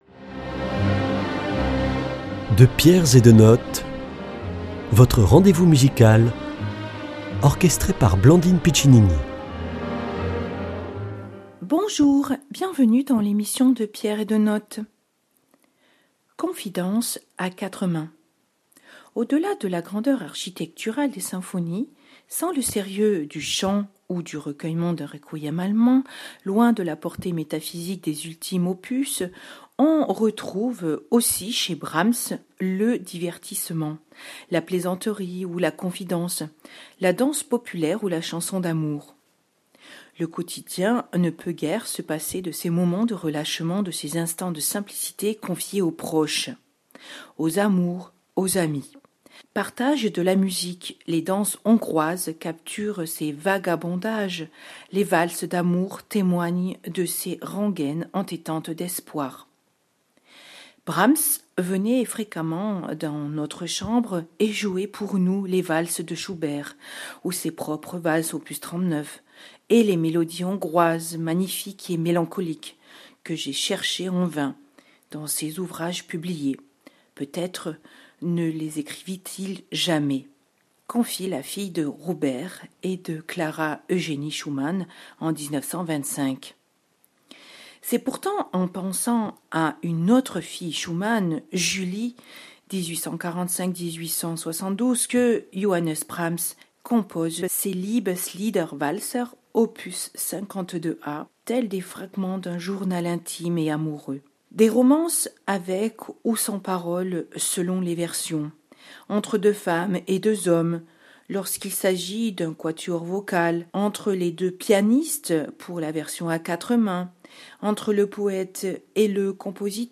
L'interprétation est pour piano à 4 mains.